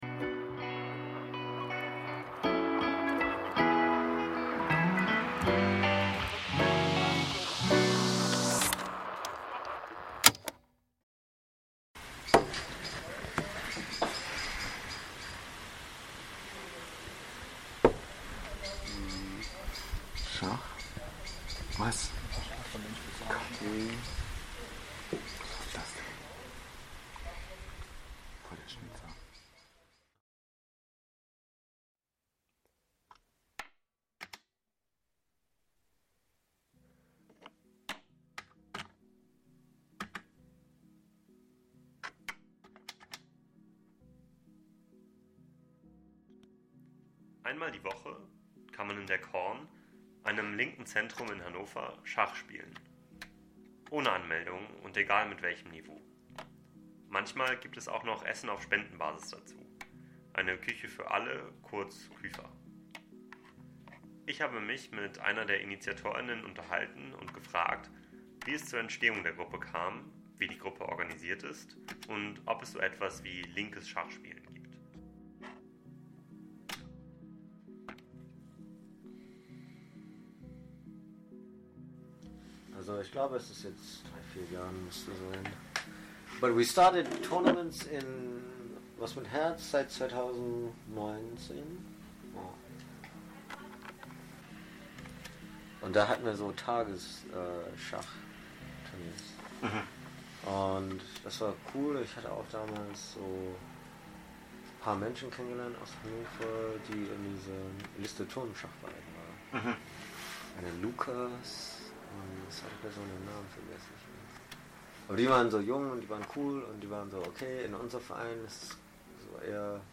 Einer von Ihnen erzählt im Mini-Podcast wie das aussieht und wie es zu dieser Idee gekommen ist.